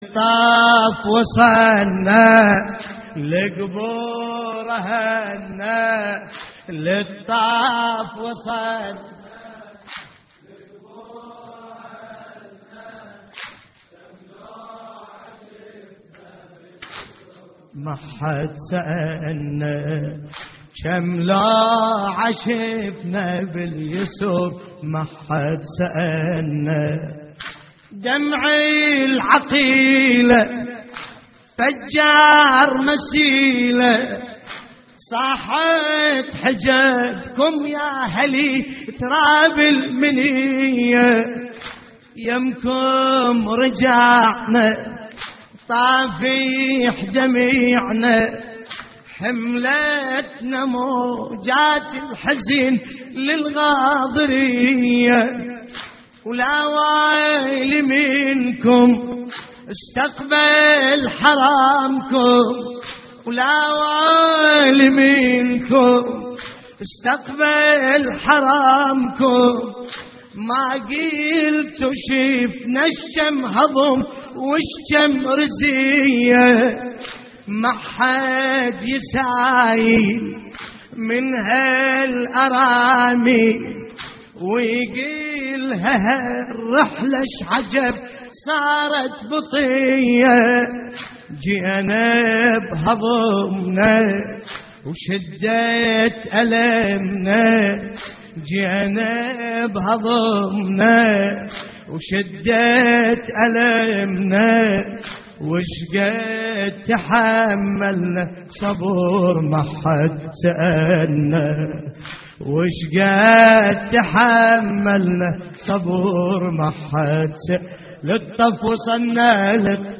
تحميل : للطف وصلنا لقبور اهلنا كم لوعة شفنا / الرادود باسم الكربلائي / اللطميات الحسينية / موقع يا حسين